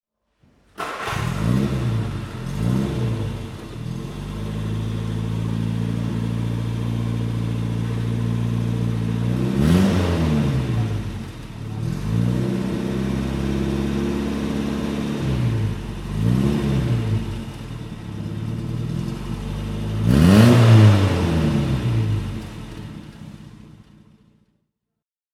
Innocenti IM3 S (1967) - Starten und Leerlauf